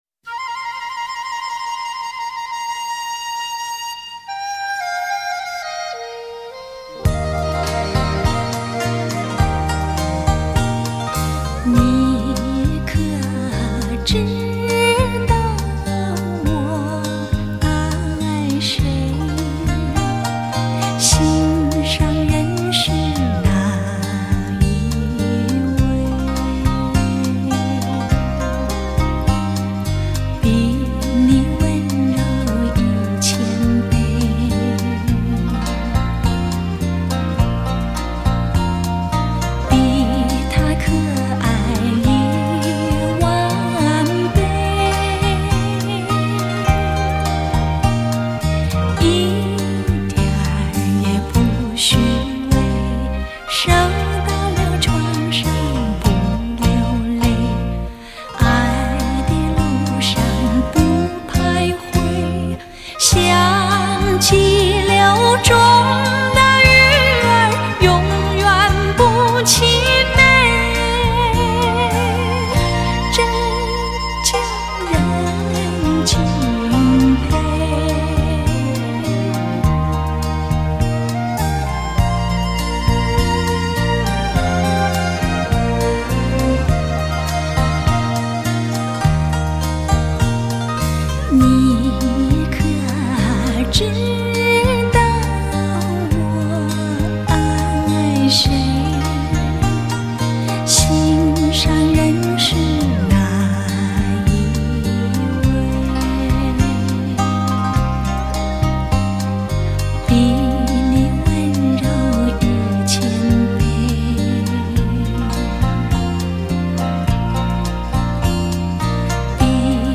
剩下透明的歌声以春风的姿态沐浴干涸